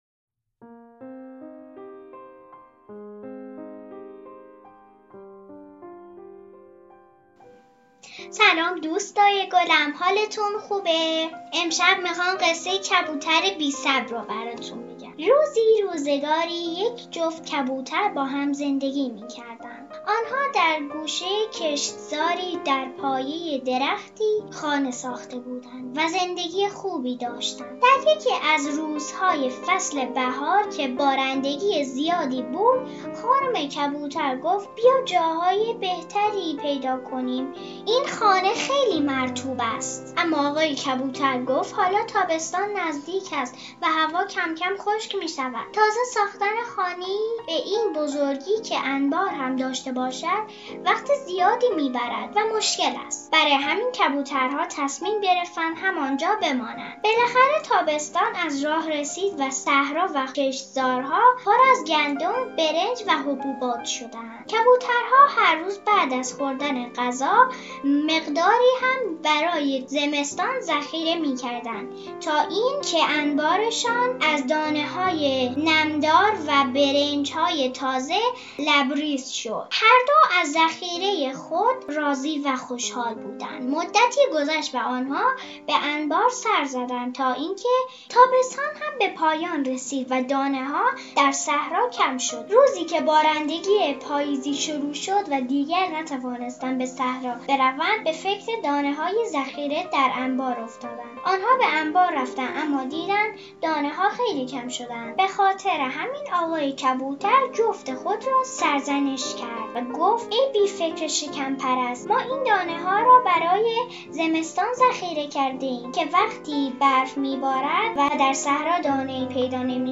قصه صوتی